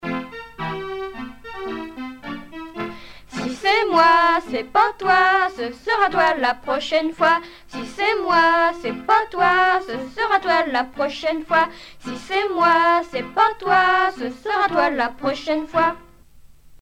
Genre brève
Pièce musicale éditée